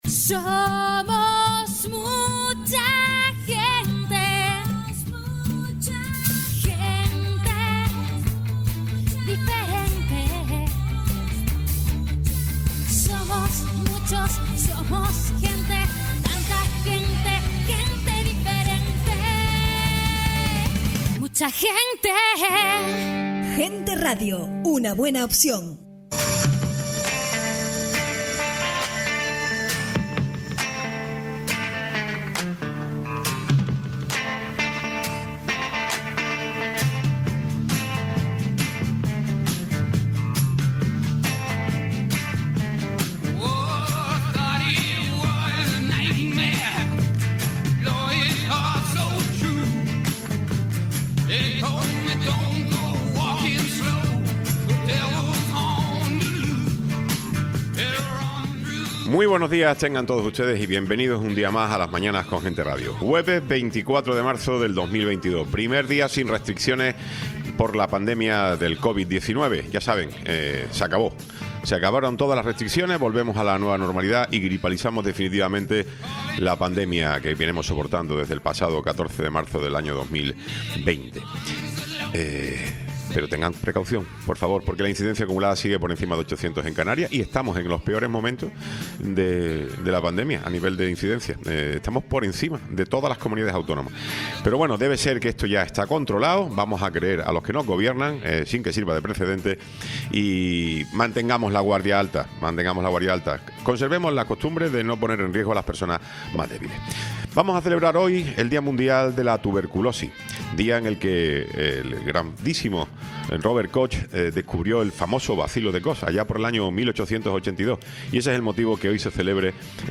Tiempo de entrevista con Vidina Espino, diputada autonómica del Grupo Mixto